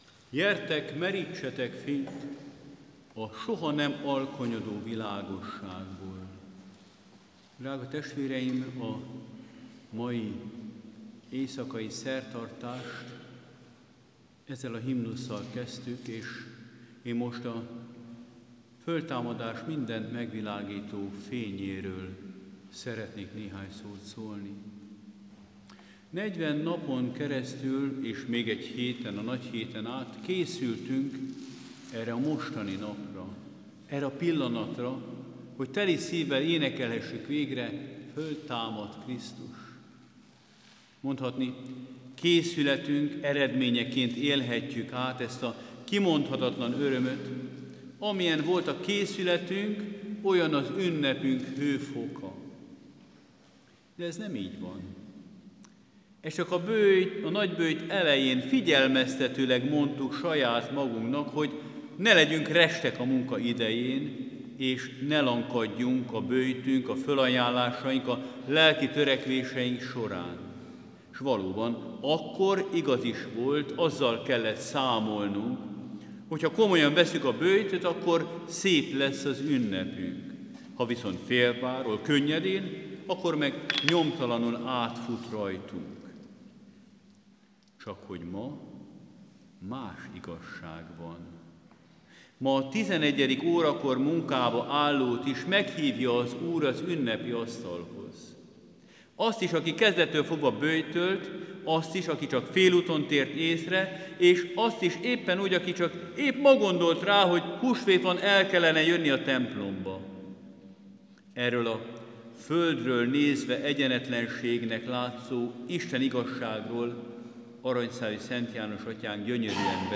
Fogadják szeretettel Kocsis Fülöp metropolita húsvéti üzenetét, amely az éjféli, feltámadási szertartás után megtartott püspöki Szent Liturgián hangzott el.